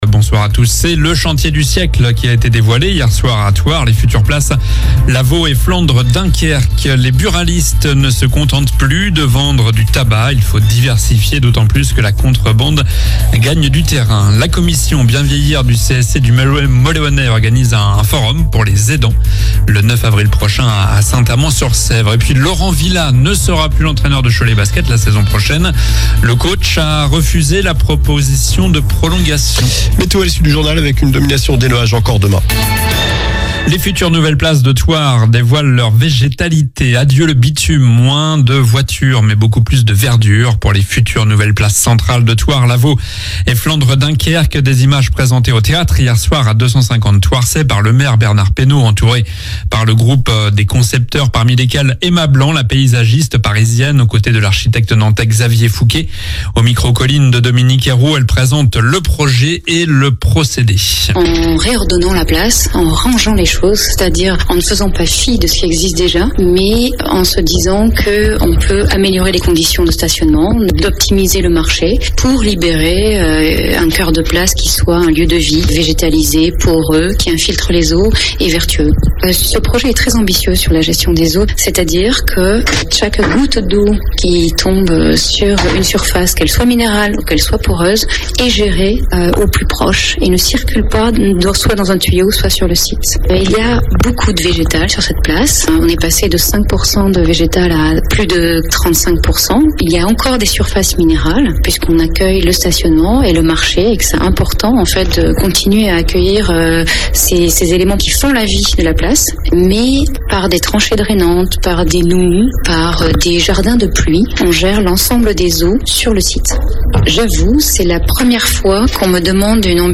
Journal du mardi 26 mars (soir)